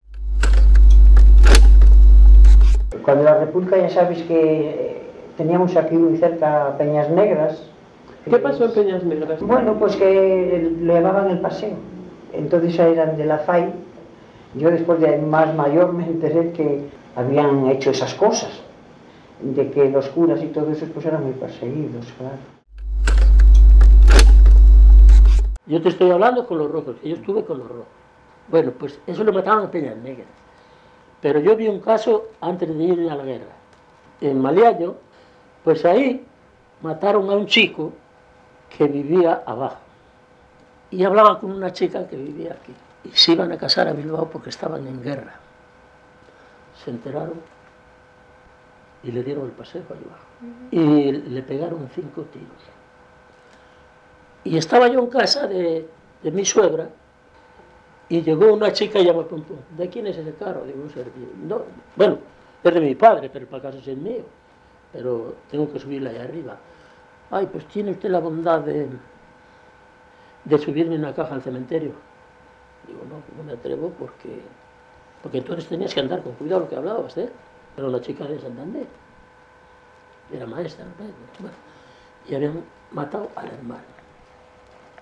Historia oral del valle de Camargo